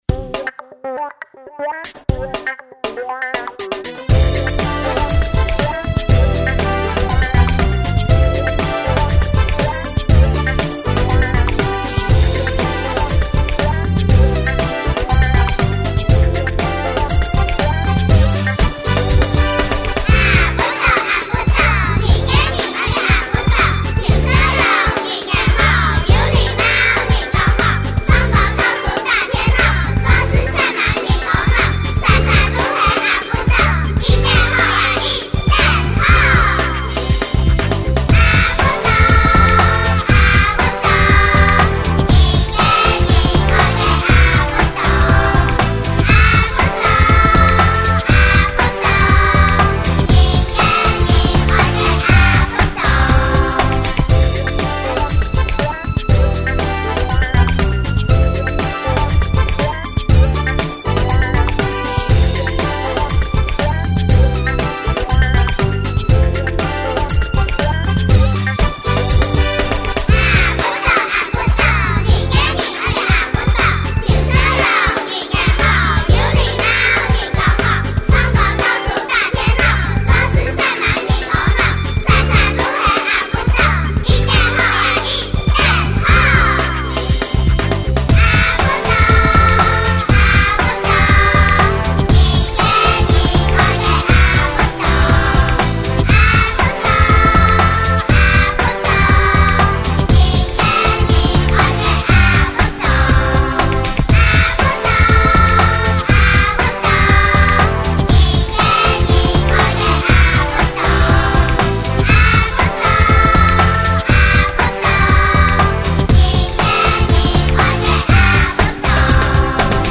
阿不倒(完整演唱版) | 新北市客家文化典藏資料庫